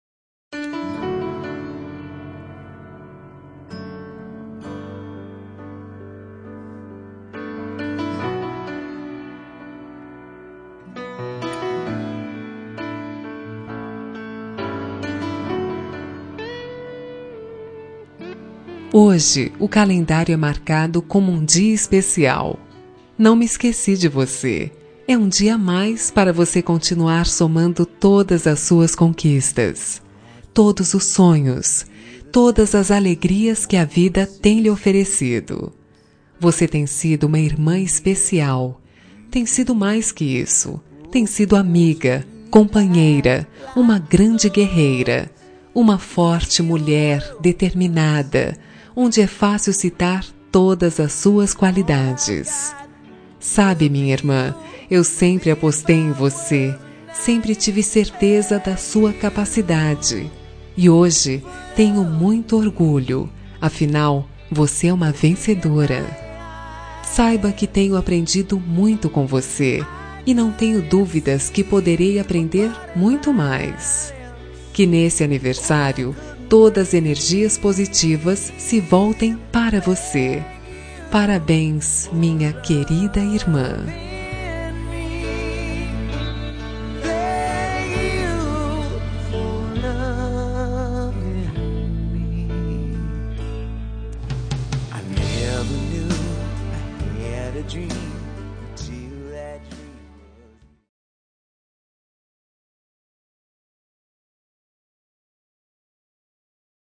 Telemensagem de Aniversário de Irmã – Voz Feminina – Cód: 1644 Linda